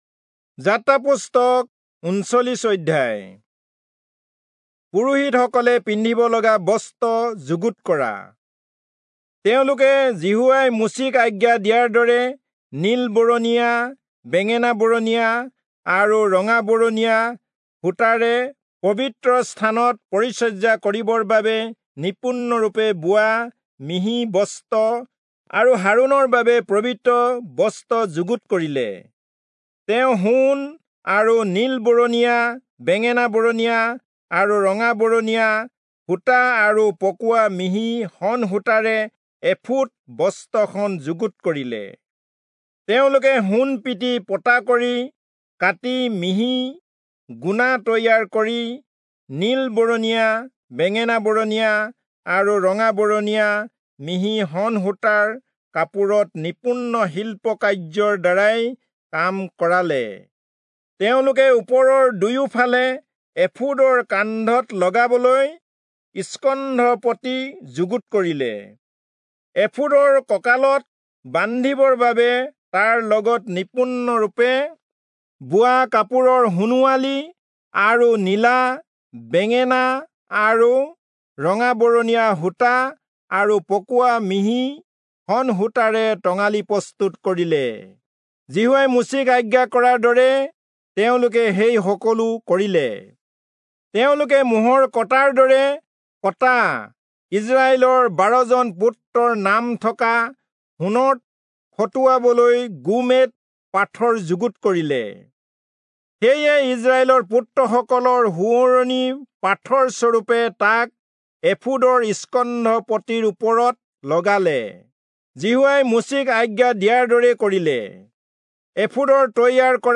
Assamese Audio Bible - Exodus 14 in Gnttrp bible version